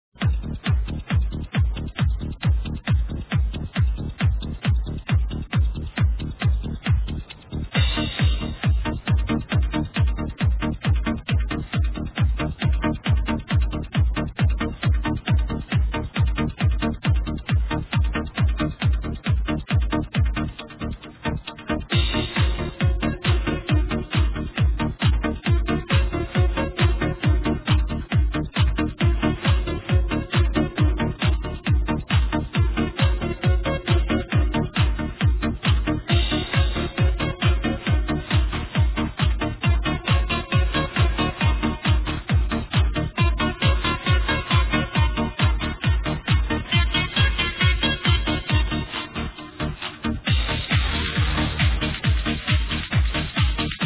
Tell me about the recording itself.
Another recording off the radio so I need a better copy.